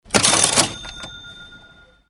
Звук кассы или звук казино 08 окт. 2023 г. На этой странице вы можете скачать звук кассы или казино Скачать (232 скачали) Касса открывается 16 дек. 2023 г. Звук открывающейся кассы Скачать (177 скачали)